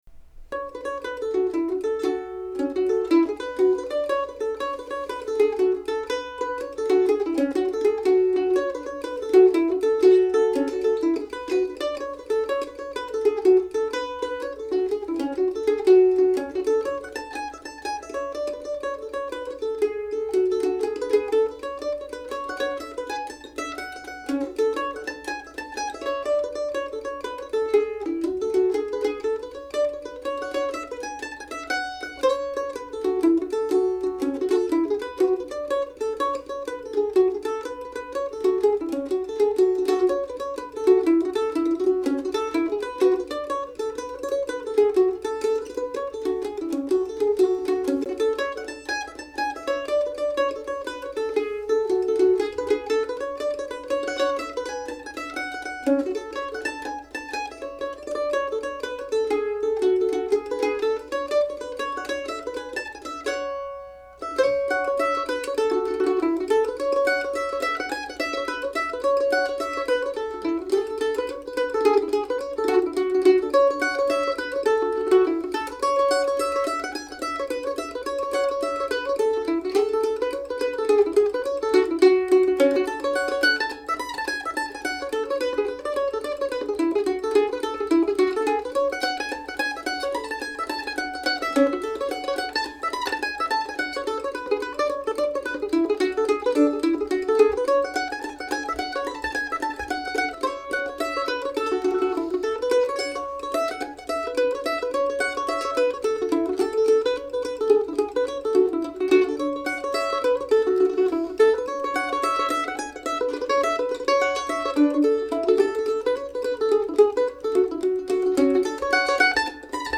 Since I had my mandolin with me and a fair bit of downtime at the orphanage, I ended up writing a couple tunes. The Rowan Tree and The Carpathian Foothills, both in F# minor, a key I've been wanting to do something with for a while.
After messing around for way too long trying to get my computer to record nicely from my microphone, I didn't feel like doing a ton of takes.